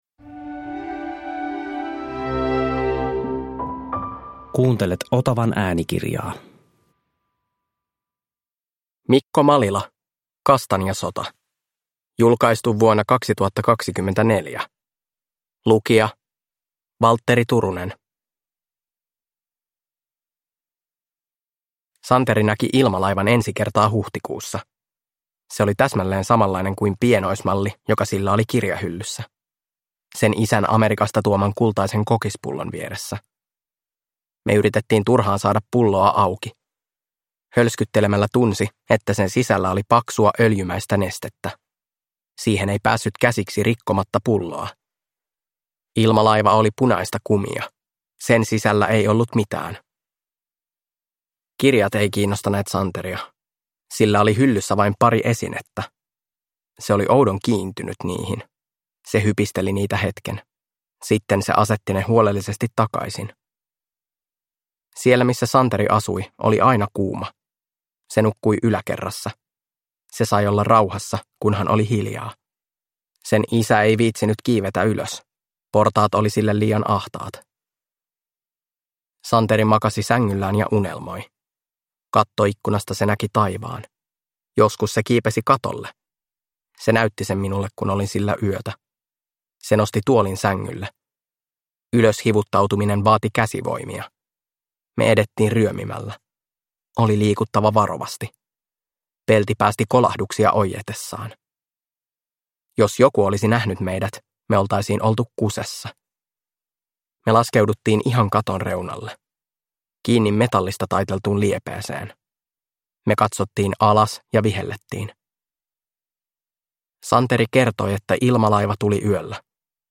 Kastanjasota – Ljudbok